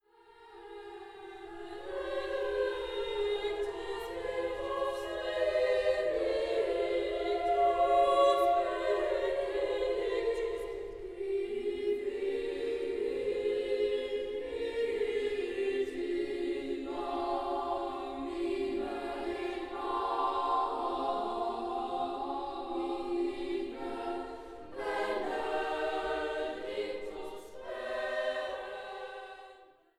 in Mitschnitten der Uraufführungen
für Chor a cappella – 3. Sanctus